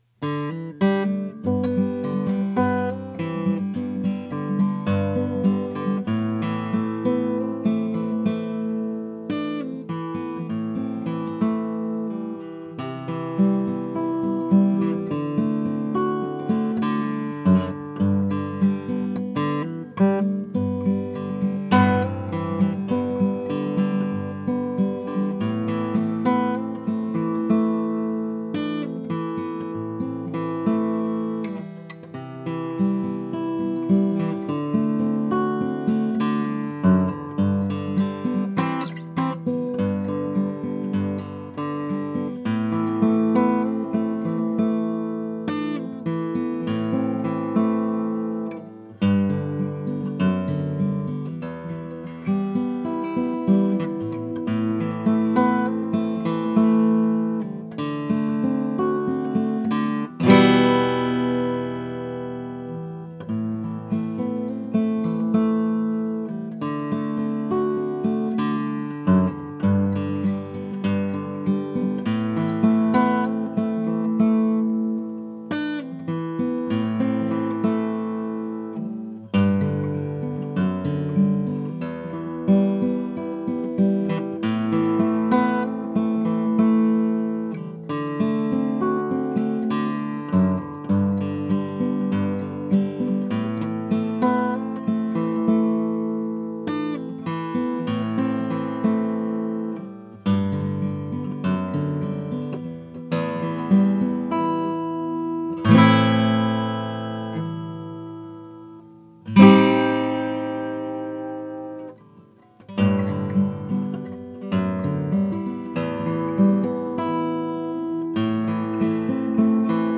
Standard X-Braced OM Guitar
Strings Light gauge phosphor bronze
• Traditional X-bracing for a balanced tone across lows, mids, and highs
• Excellent note separation and clarity
Warm, balanced, and articulate — the Standard OM offers a rich tonal response without overwhelming bass. Each note rings clear, making it ideal for solo acoustic performances, recording sessions, or small ensemble work.
X-Braced-OM-Sound-Sample.mp3